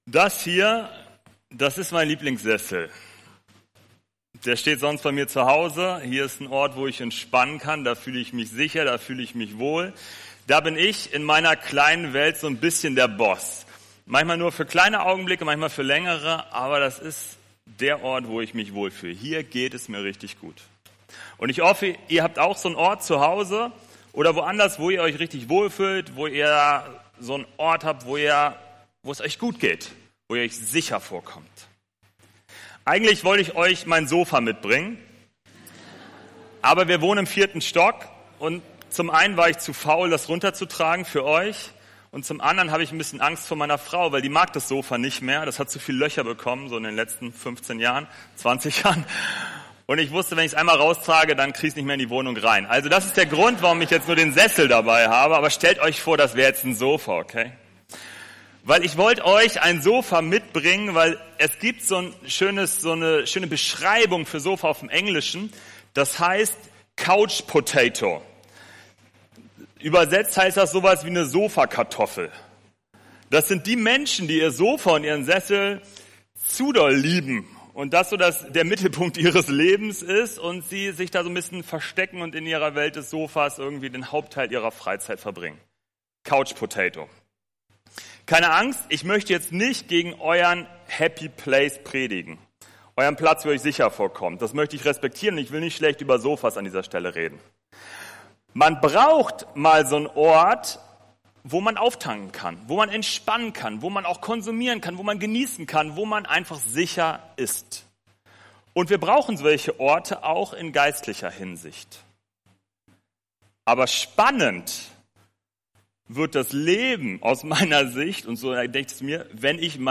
Predigttext: 1. Samuel 14, 1+4+6-7+11+23; Römer 8, 38-39; Richter 7, 2